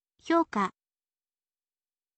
hyouka